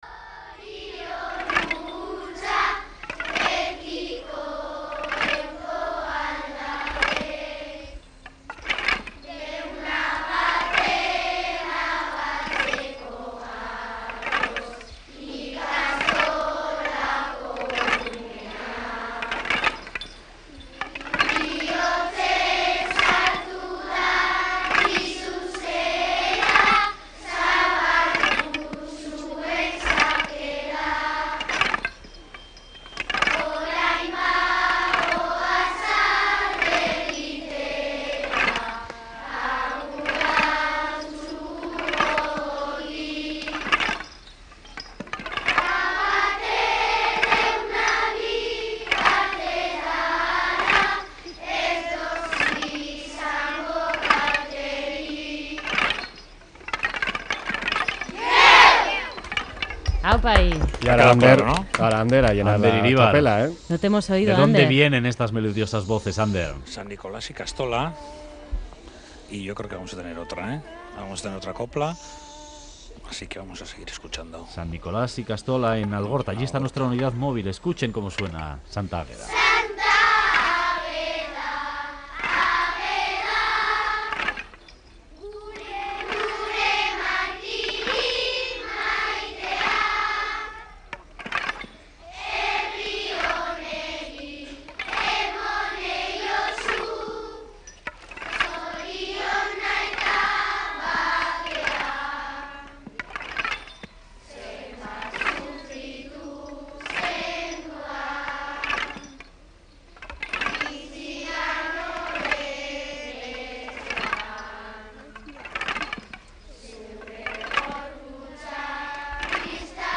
Alumnos de la Ikastola San Nikolas cantando Santa Águeda
Con motivo de la víspera de Santa Águeda, alumnos de la ikastola San Nikolas de Getxo han salido a la calle a cantar las habituales coplas.
La Unidad Móvil de Radio Euskadi ha estado con ellos.